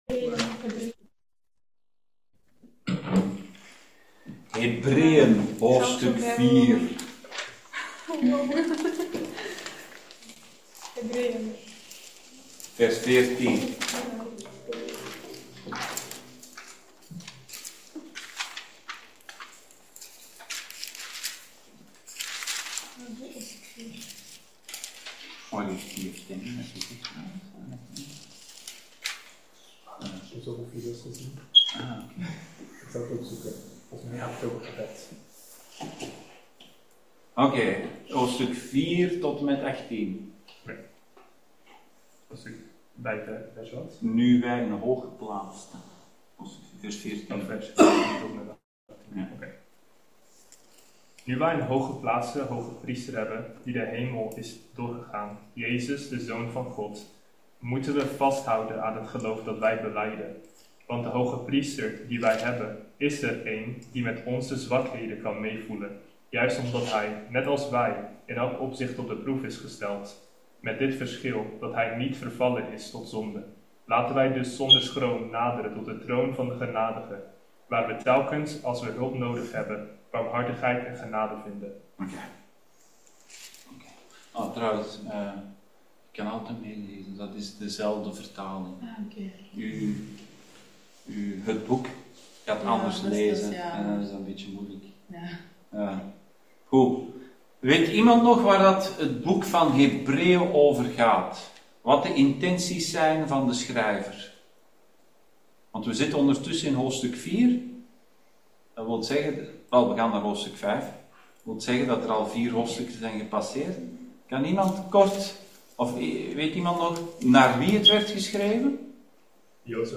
Hebreeën Dienstsoort: Bijbelstudie « De Tabernakel